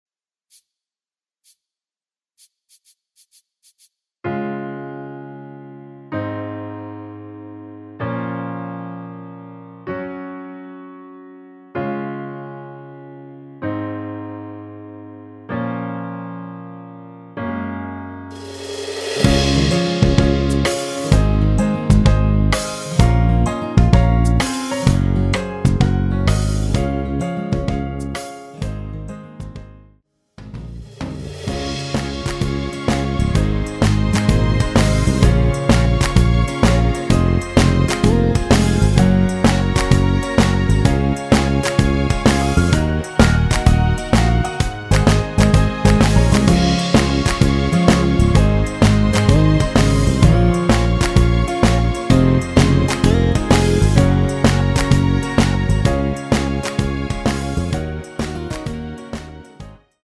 전주 없이 시작 하는 곡이라 전주2마디 만들어 놓았습니다.
C#
앞부분30초, 뒷부분30초씩 편집해서 올려 드리고 있습니다.
중간에 음이 끈어지고 다시 나오는 이유는